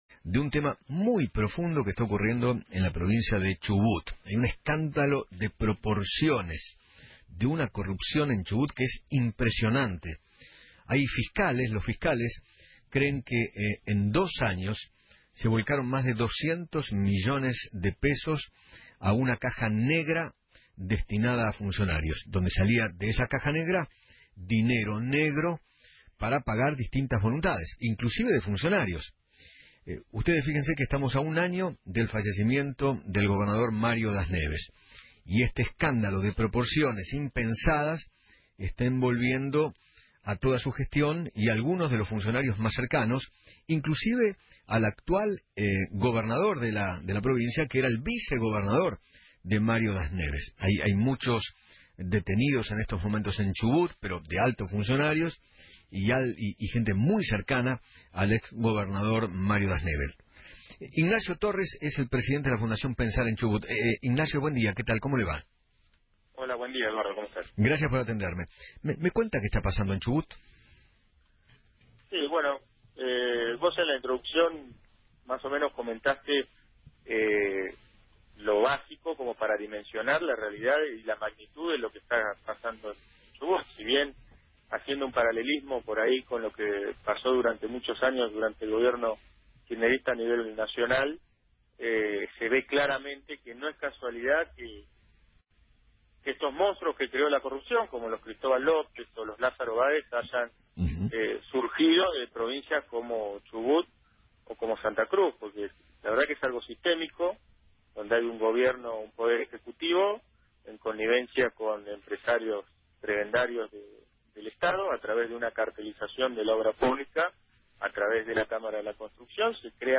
Ignacio Torres, Presidente de la Fundación Pensar en Chubut, habló en Feinmann 910 y dijo que  “Es parecido lo que pasó a nivel nacional con los Kirchner.